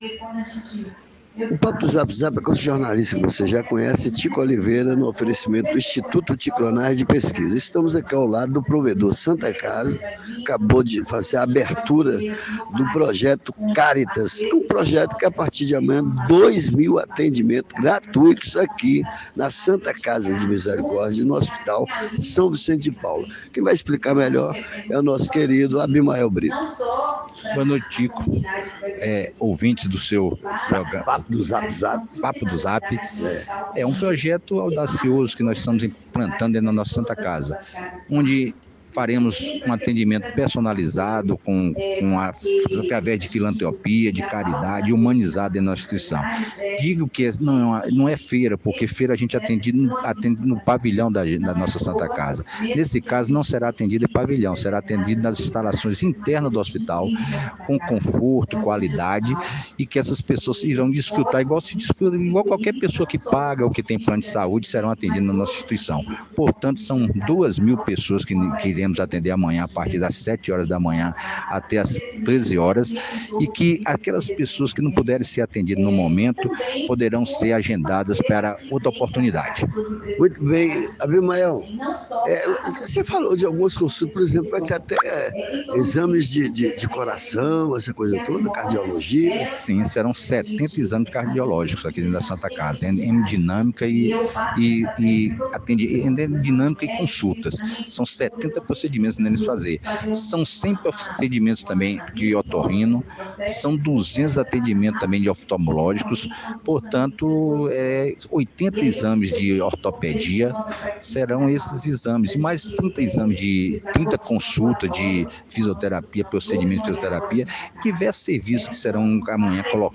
trocou uma ideia na abertura do evento nesta Segunda feira